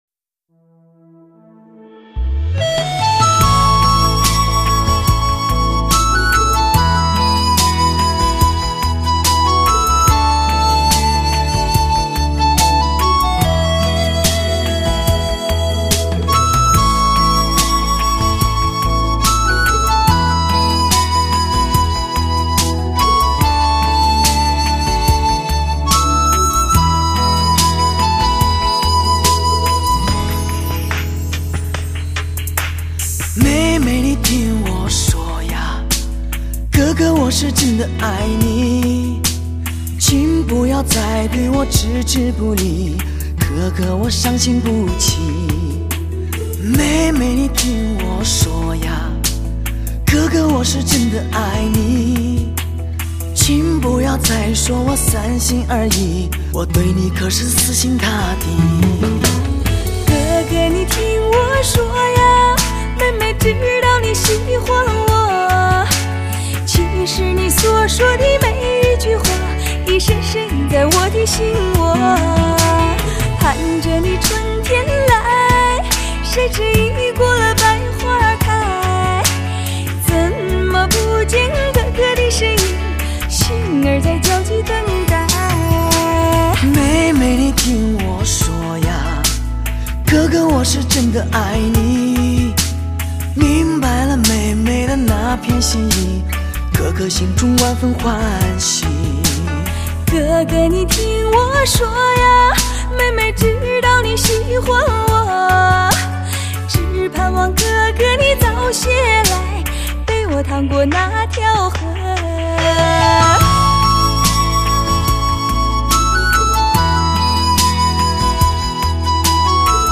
唱片类型：华语流行